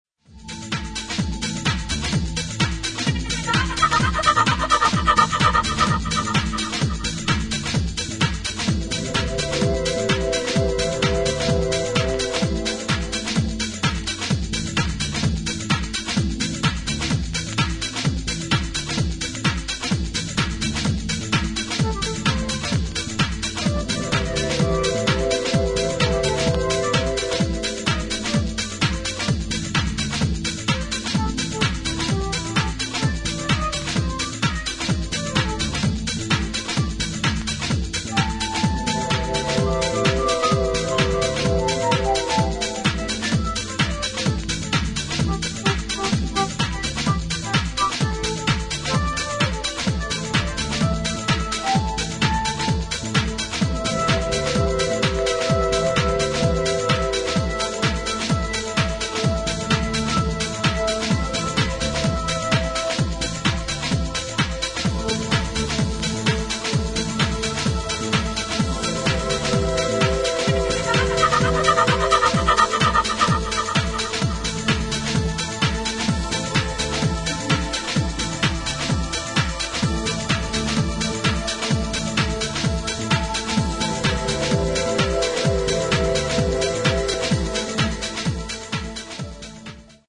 先見性のあるオリジナリティと、普遍的なダンスミュージックの魅力を内包させた秀逸な一枚です。